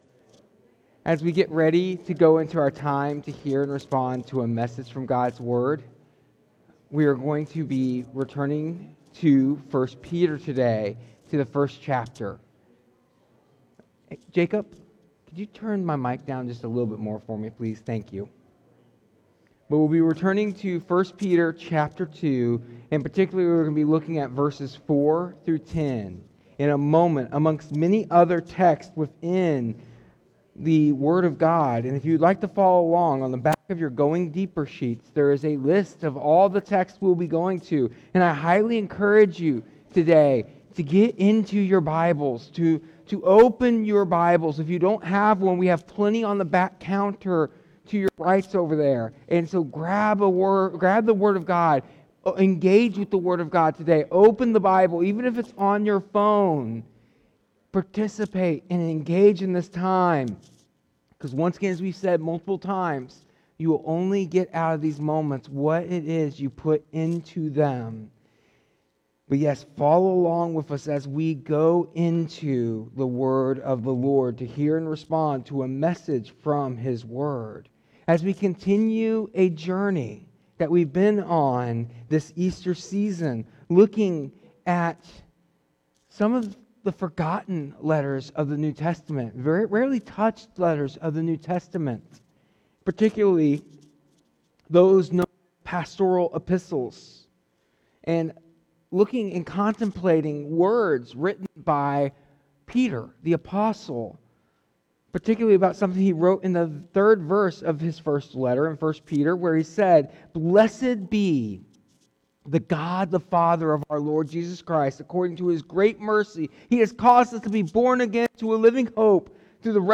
In this sermon, we continue our Easter season series exploring 1st Peter and discuss that, through the resurrection of Jesus Christ, believers are born again into a living hope that is not just a future but a present reality. Drawing from 1 Peter 2:4-10, we learn that we have been given a wondrous new identity and purpose in this.